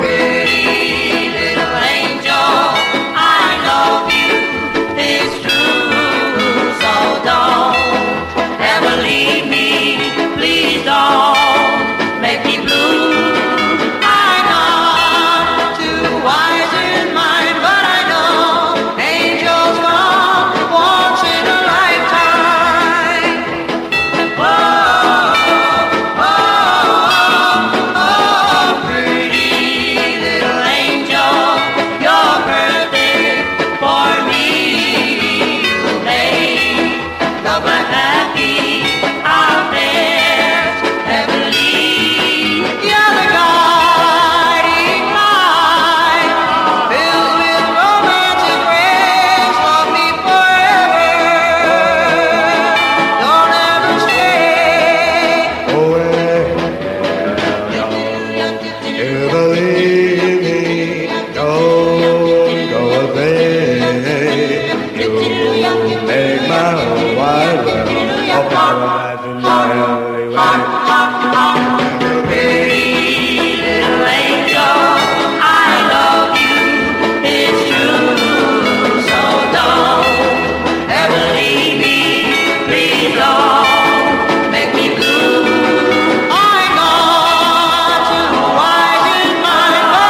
SOUL / OLDIES / DOO WOP (US)